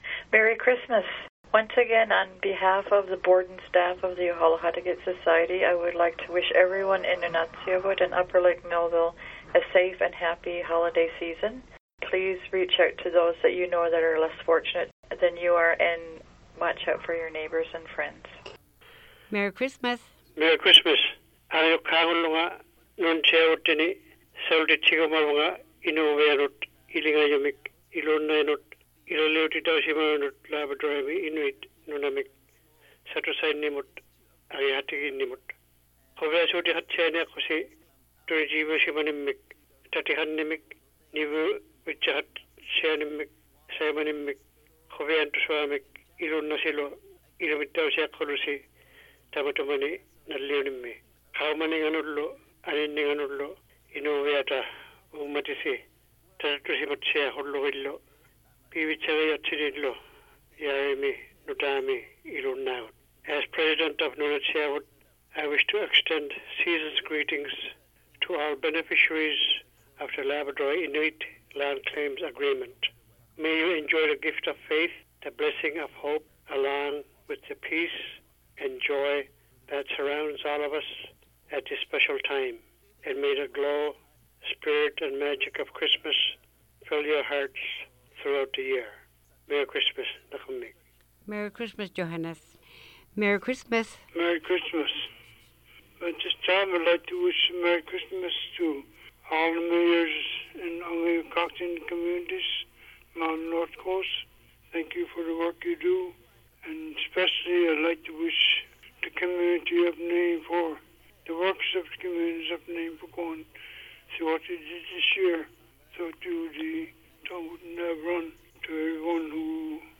OKalaKatiget Radio will be airing the first recorded Christmas Greetings from businesses.